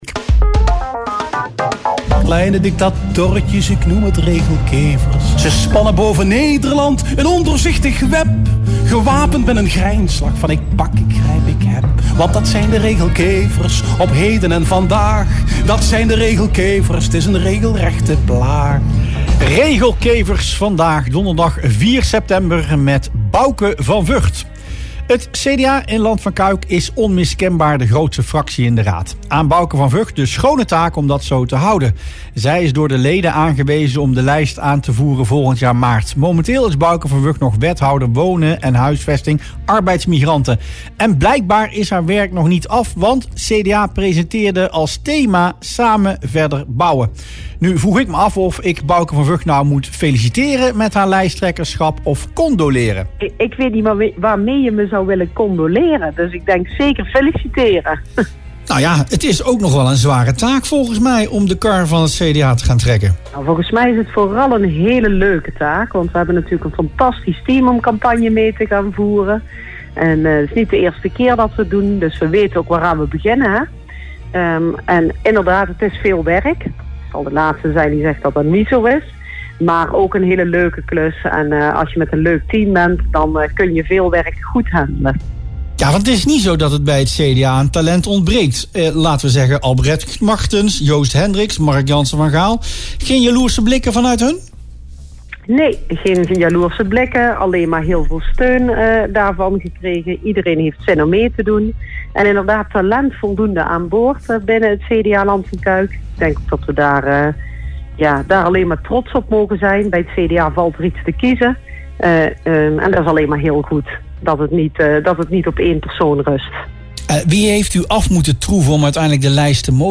BOXMEER CDA-lijsttrekker Bouke van Vught mikt bij de gemeenteraadsverkiezingen van maart 2026 op veertien zetels. Dat zei zij in radioprogramma Rustplaats Lokkant. Van Vught wil daarnaast graag terugkeren als wethouder wonen, een functie die zij nu ook bekleedt.
CDA-lijsttrekker Bouke van Vught in Rustplaats Lokkant